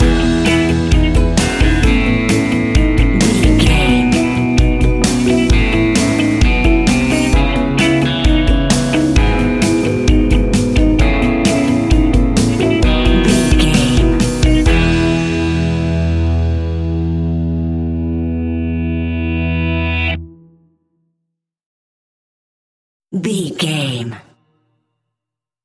Uplifting
Ionian/Major
pop rock
indie pop
fun
energetic
cheesy
guitars
bass
drums
piano
organ